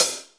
Index of /90_sSampleCDs/Total_Drum&Bass/Drums/HiHats
1a_closed.wav